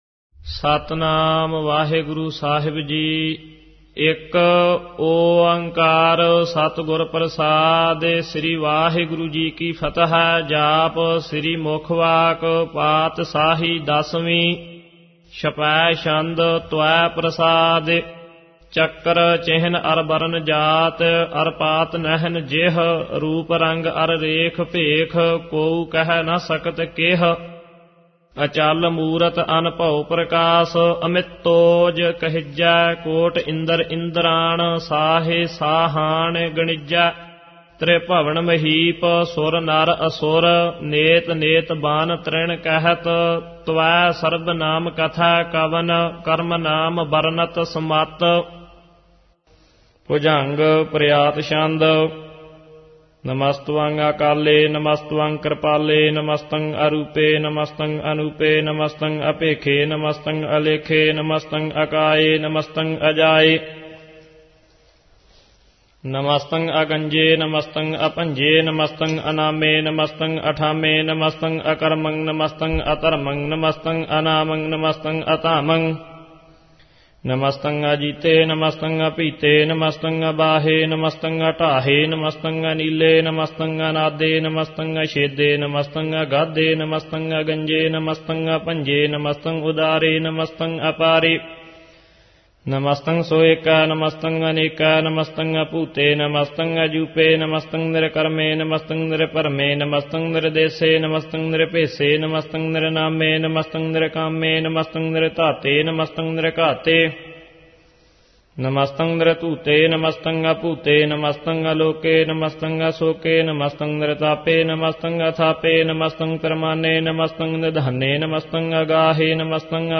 Download and Listen -Gurbani Ucharan(Paath Sahib